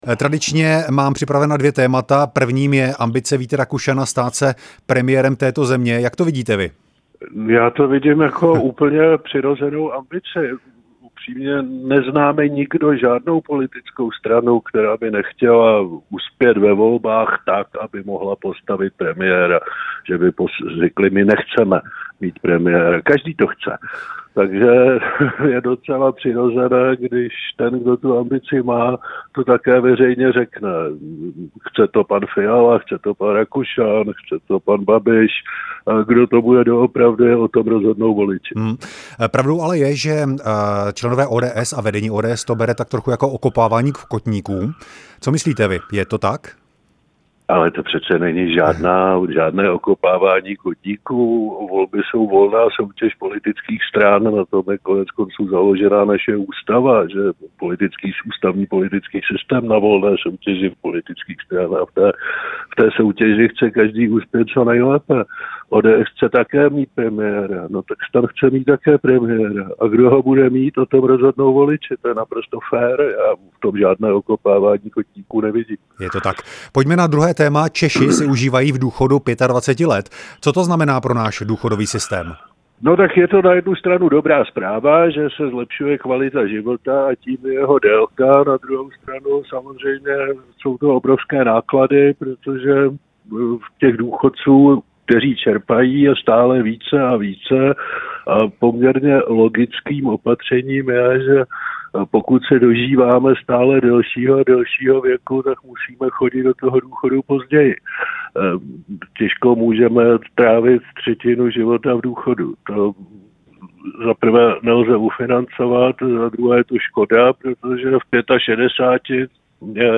Pravidelným hostem pondělního ranního vysílání rádia Prostor je Miroslav Kalousek. Mezi tématy rozhovoru byla ambice ministra vnitra Víta Rakušana stát se premiérem a také to, jak dlouho tráví Češi v důchodu a co to dělá s naším důchodovým systémem.